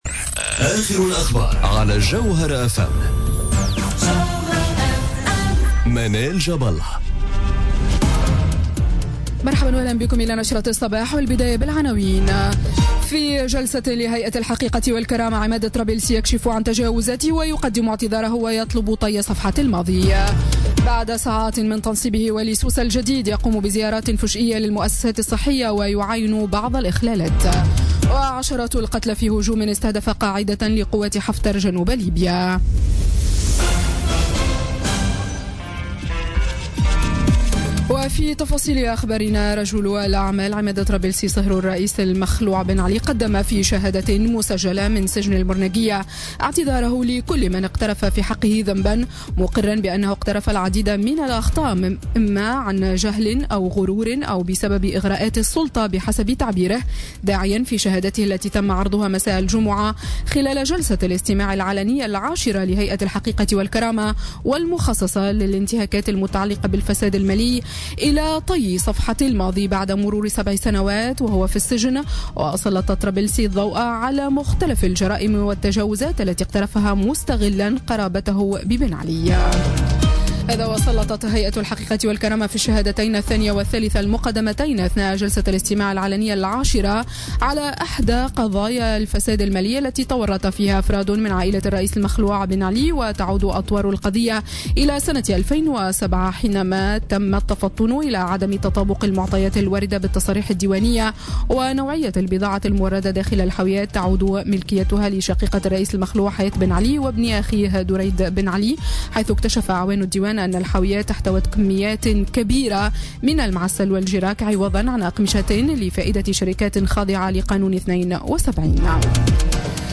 نشرة أخبار السابعة صباحا ليوم السبت 20 ماي 2017